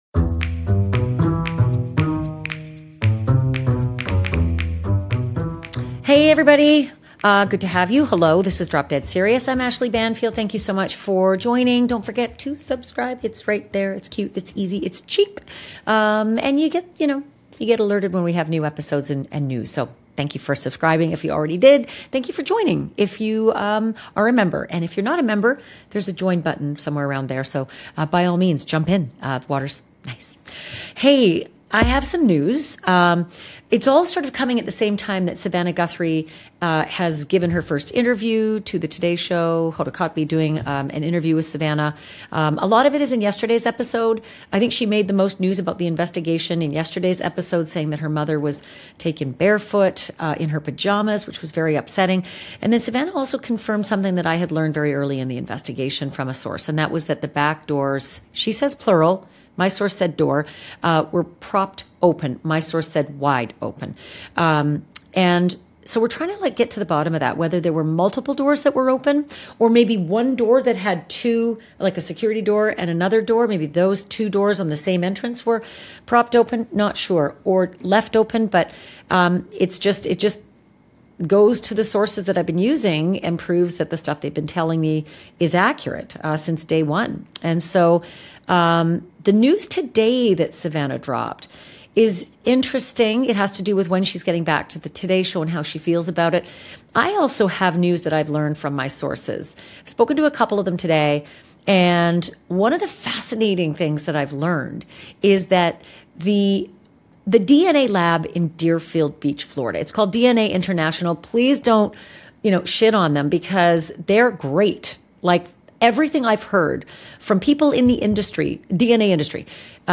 In this episode of Drop Dead Serious, Ashleigh is joined by renowned investigative genetic genealogist CeCe Moore, the chief genetic genealogist at Parabon NanoLabs, whose work using DNA and family-tree analysis has helped identify suspects and victims in hundreds of criminal cases.